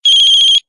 Cricket.ogg